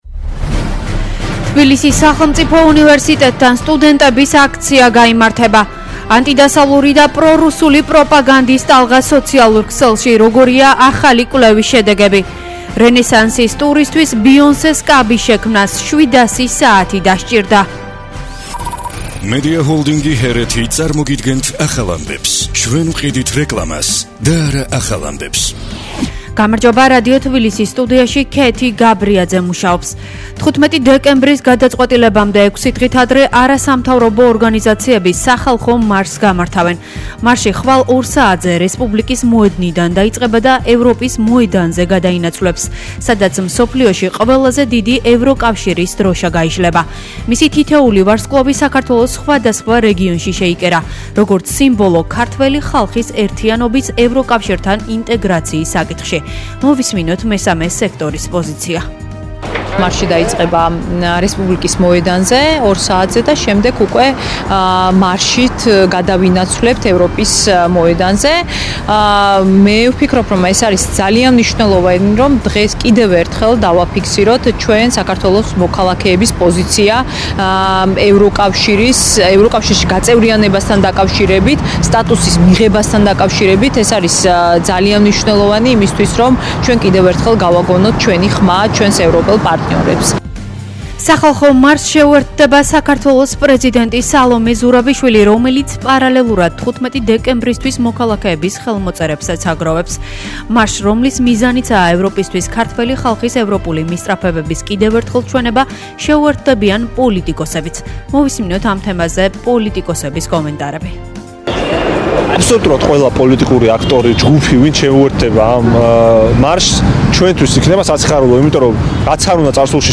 ახალი ამბები 12:00 საათზე